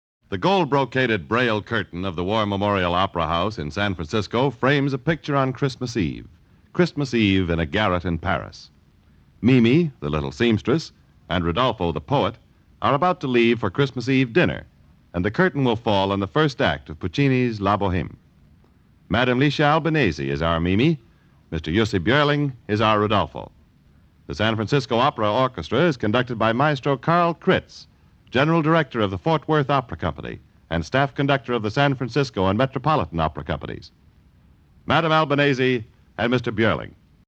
Den amerikanske hallåmannens presentation av duetten